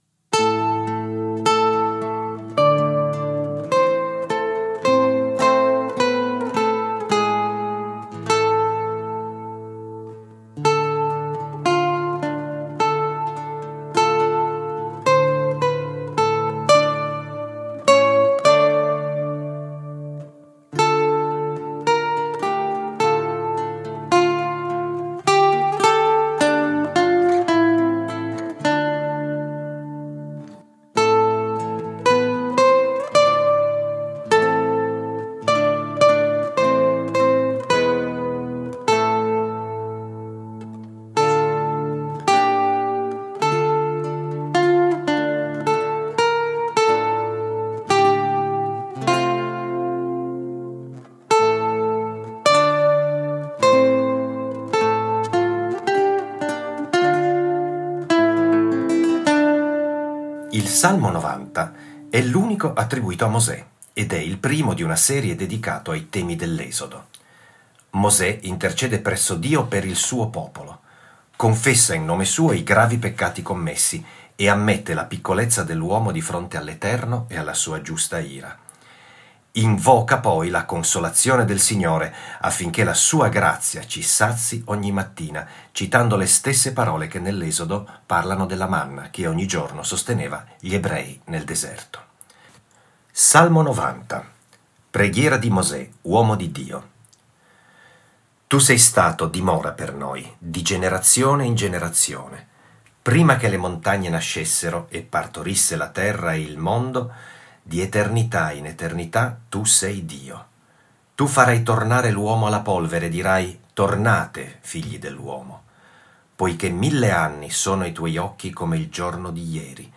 Salmi cantati
con accompagnamento della chitarra